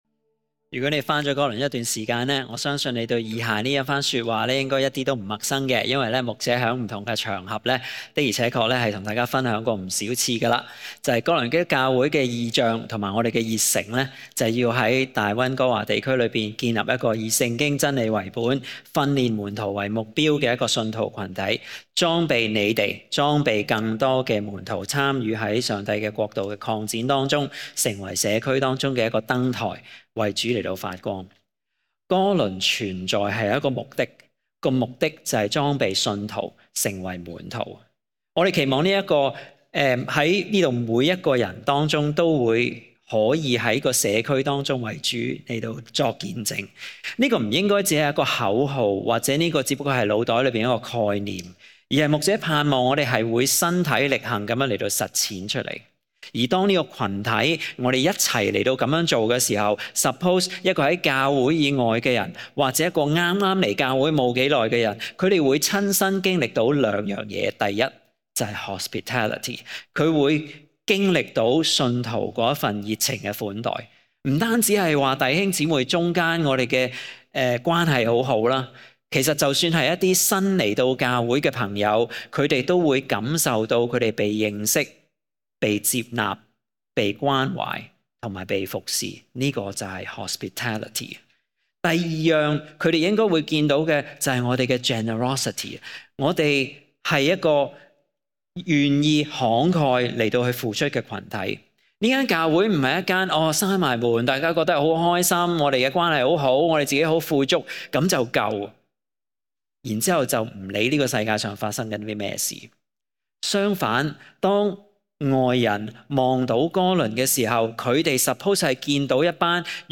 * 本週 YouTube 重温內容祇包括講道錄音，敬請留意 *